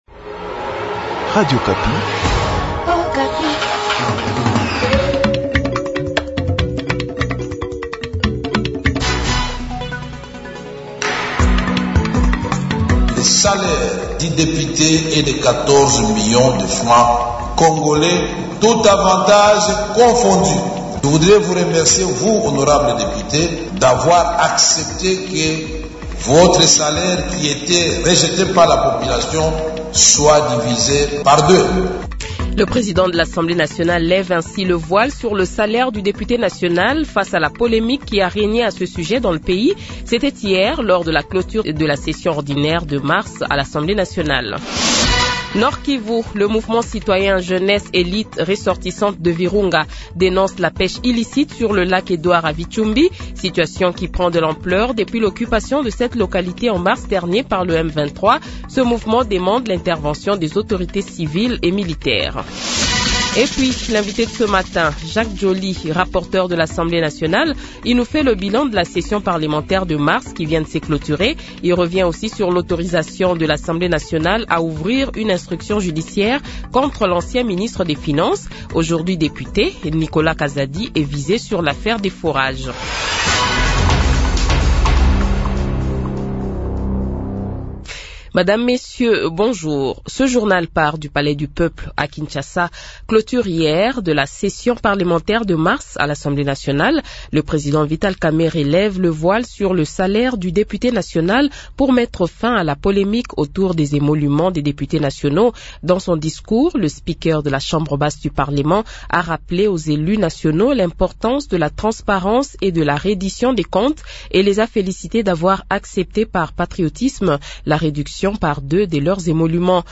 Invité : Jacques Djoli, rapporteur de l’Assemblée nationale